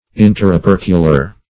Search Result for " interopercular" : The Collaborative International Dictionary of English v.0.48: Interopercular \In`ter*o*per"cu*lar\, a. Of or pertaining to the interoperculum.
interopercular.mp3